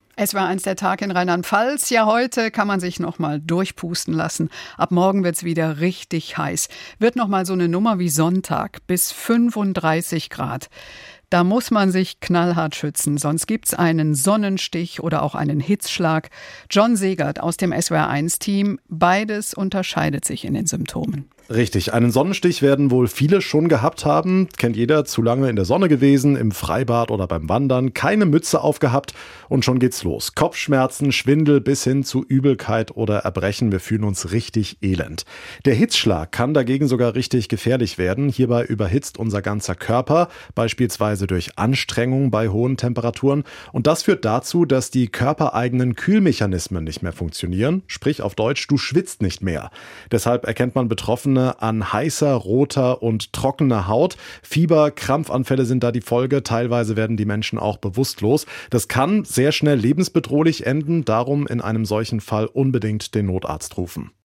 Das Gespräch führte